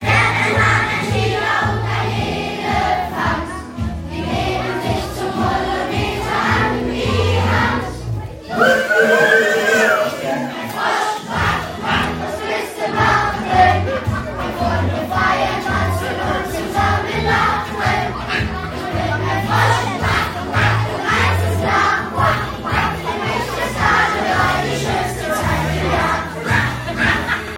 Am Freitag, den 13.02.2026 feierten wir einen tollen Tag mit guter Laune und lustigen musikalischen Beiträgen!
Die Stimmung war großartig!